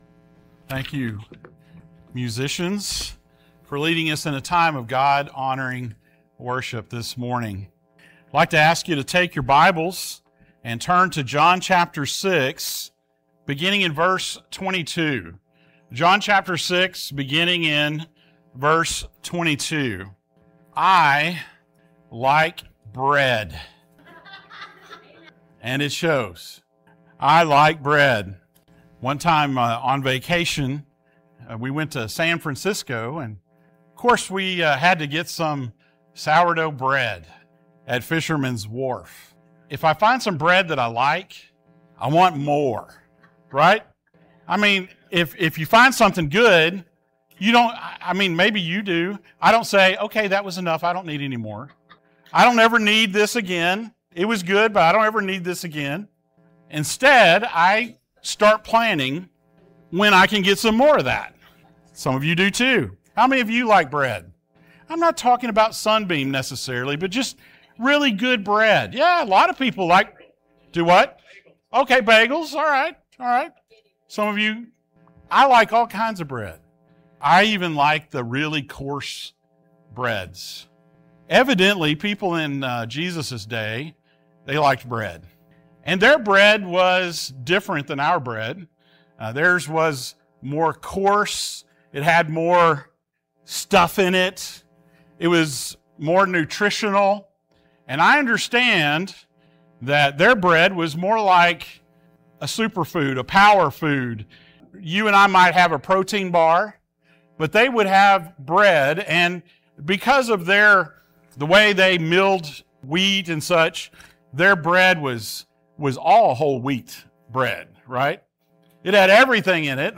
The Life and Ministry of Jesus Passage: John 6:22-33 Service Type: Sunday Morning Thank you for joining us.